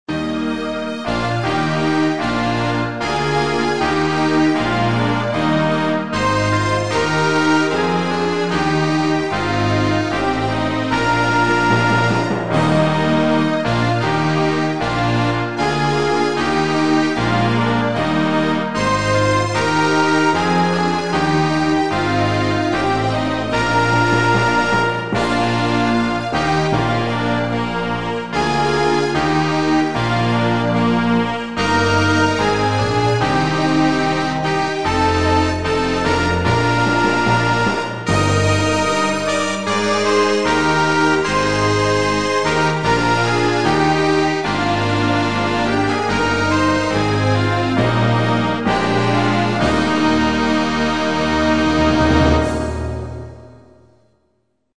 Inno nazionale